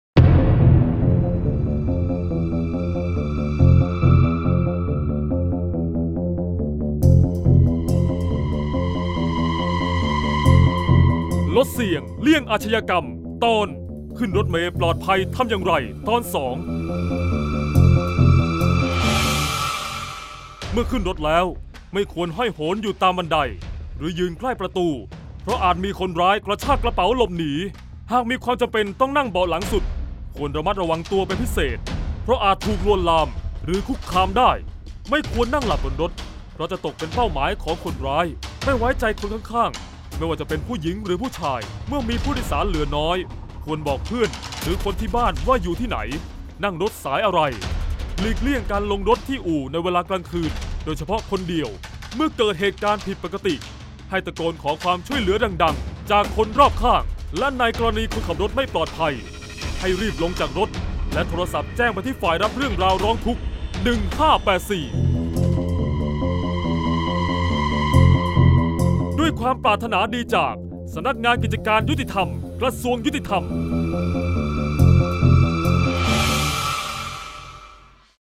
เสียงบรรยาย ลดเสี่ยงเลี่ยงอาชญากรรม 20-ขึ้นรถเมย์ปลอดภัย-2